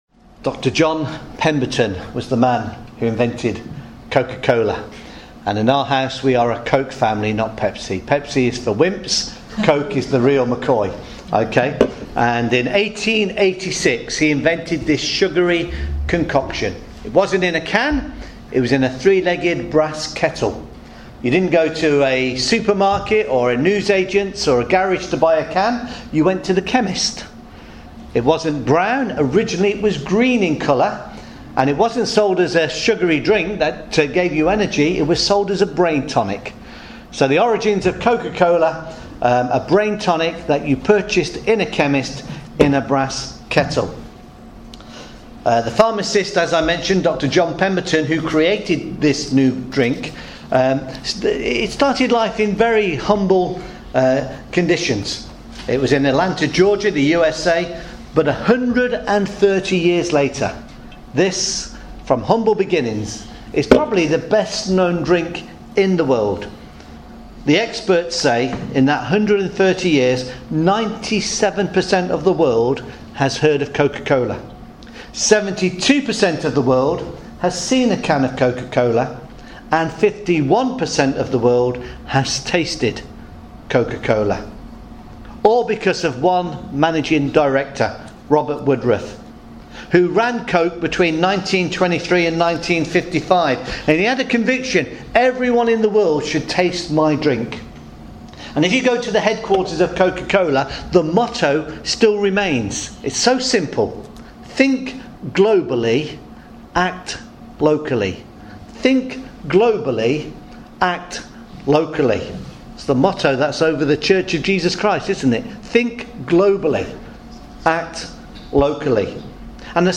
Matthew chapter 28 verses 16 to 20 – sermon